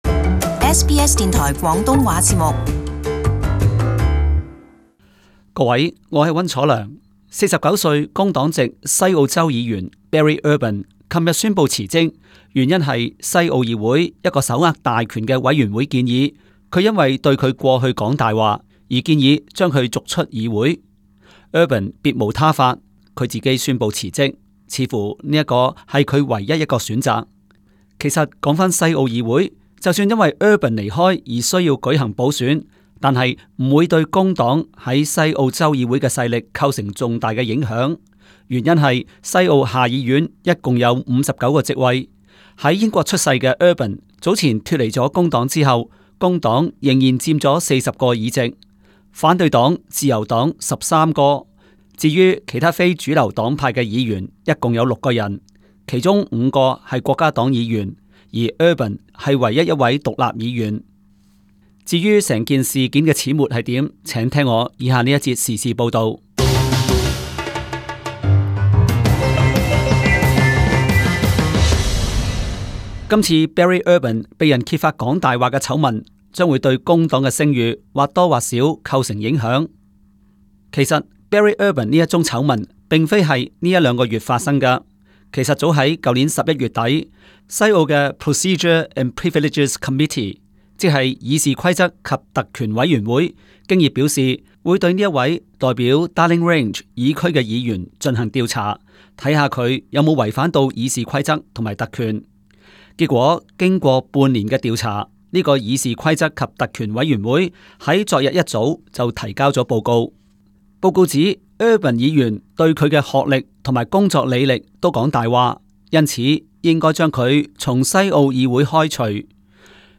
【時事報導】西澳工黨議員Barry Urban突然宣佈辭去公職